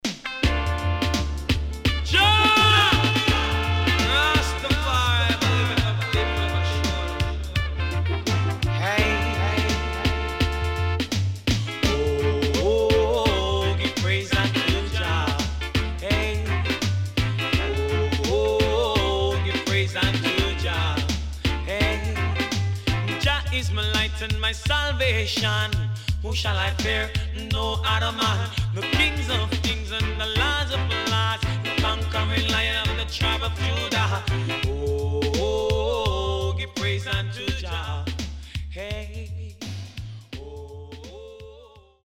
SIDE B:うすいこまかい傷ありますがノイズあまり目立ちません。